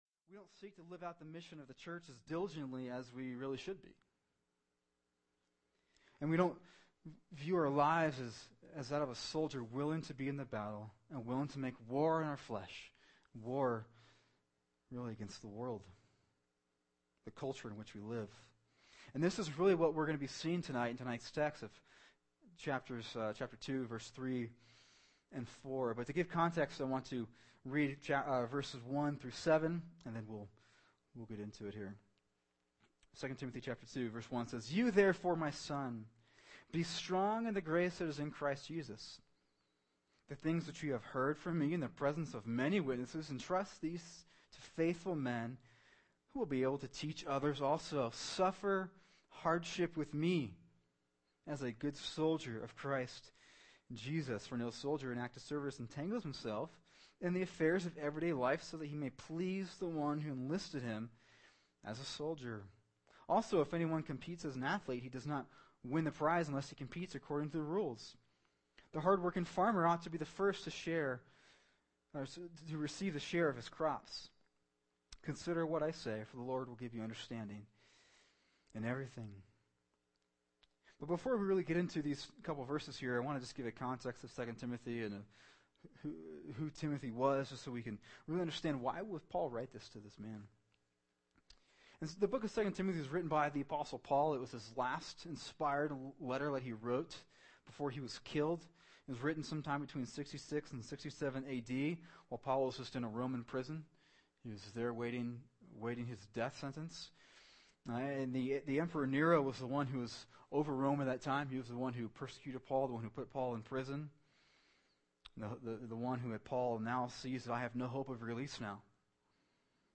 [sermon] 2 Timothy 2:3-4 “The Christian as Soldier” | Cornerstone Church - Jackson Hole